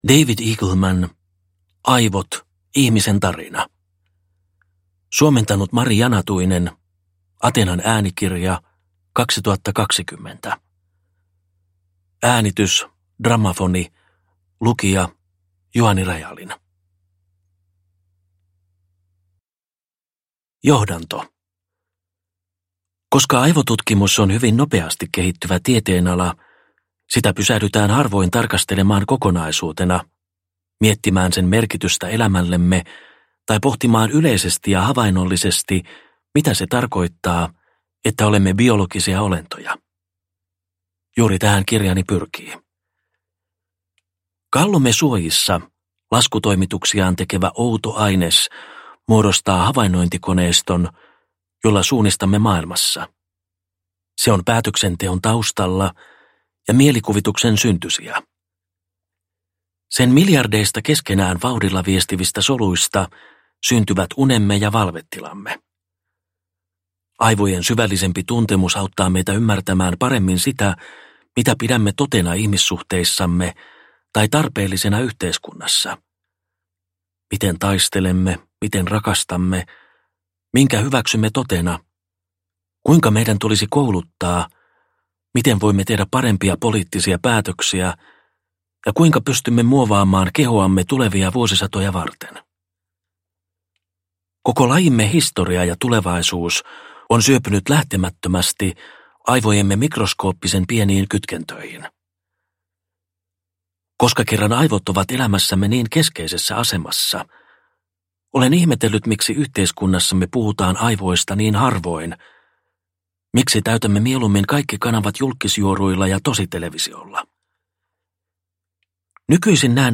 Aivot - Ihmisen tarina – Ljudbok – Laddas ner